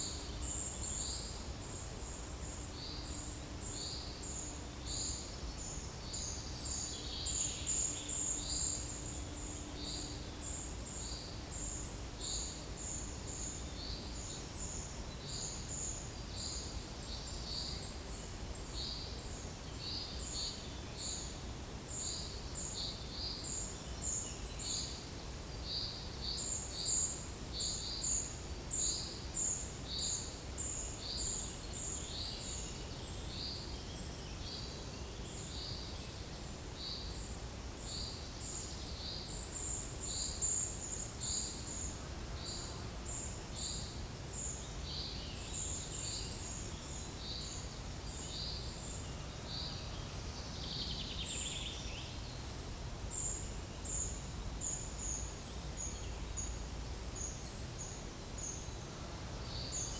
Monitor PAM
Certhia brachydactyla
Certhia familiaris
Turdus iliacus